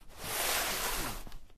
wall_slide.ogg